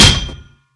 metal2.ogg